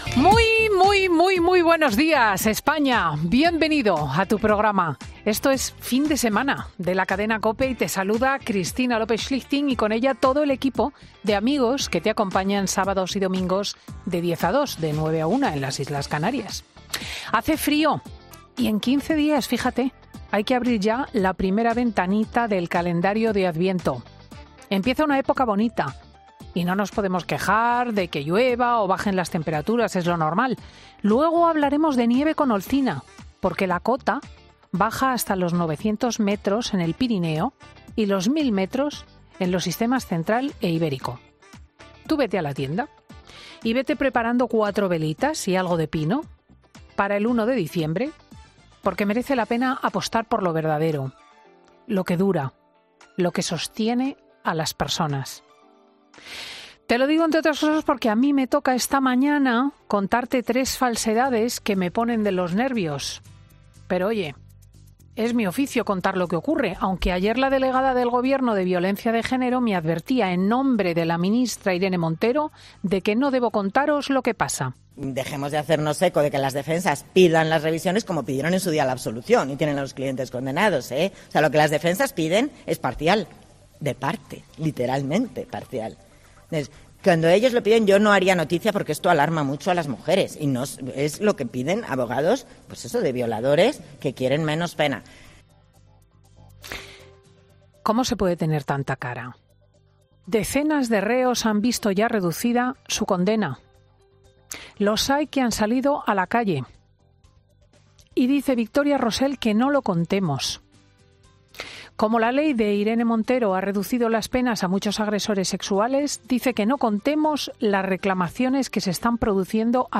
Escucha el monólogo de Cristina López Schlichting de este sábado 19 de noviembre de 2022